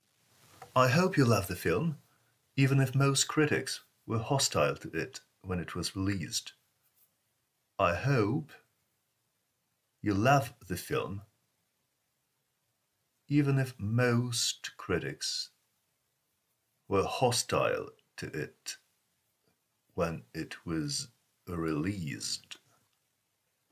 Pronunciation : the letter O :